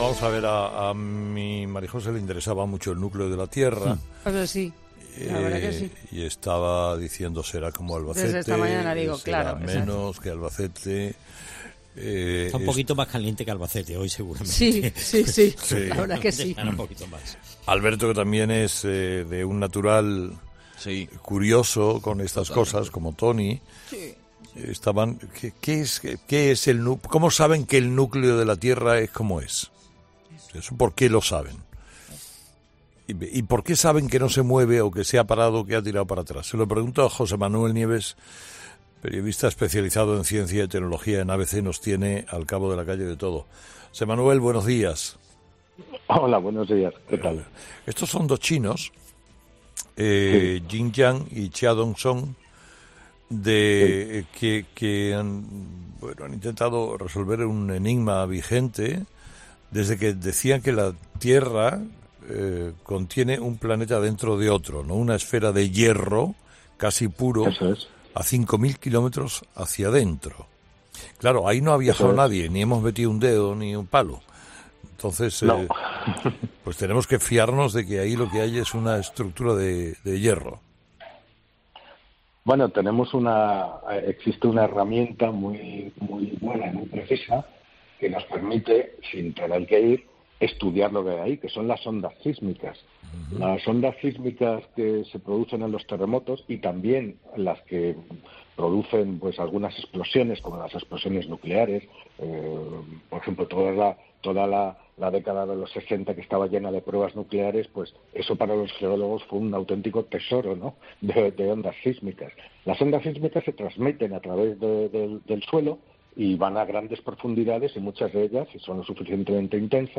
¿Días más cortos? Un experto explica cómo te afecta el cambio del movimiento del núcleo de la Tierra